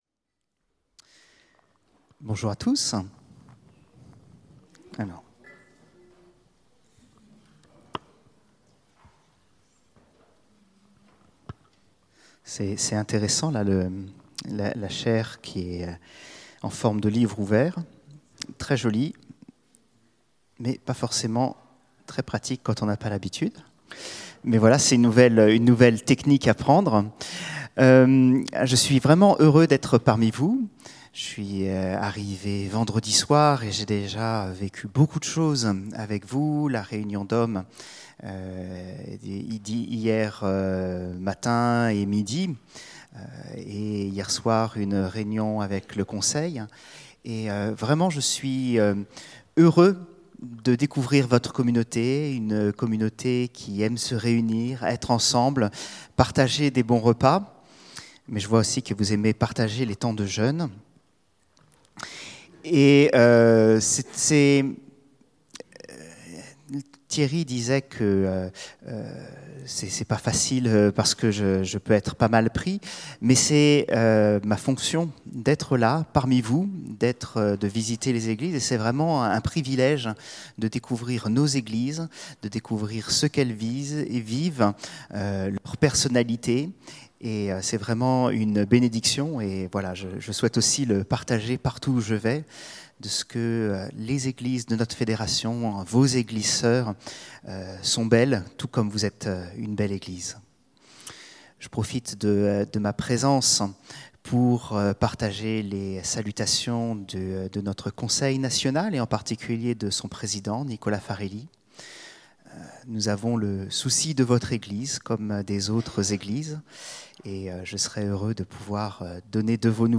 Predication-2025-05-04.mp3